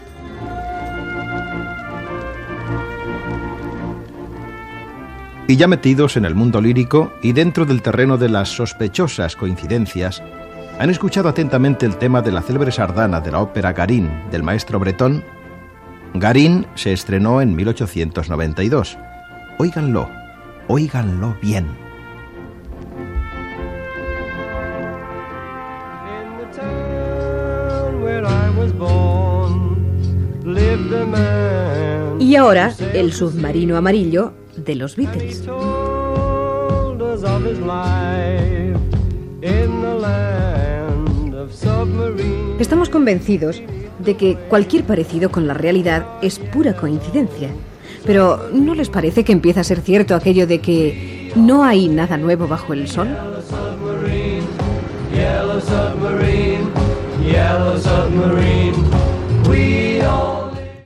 La melodia d'una sardana de l'òpera "Garín o El eremita de Montserrat" (1892), del mestre Tomás Bretón, que s'assembla a la de la cançó "Yelllow submarine" de The Beatles
Fragment extret del programa "Audios para recordar" de Radio 5 emès el 23 de novembre del 2012.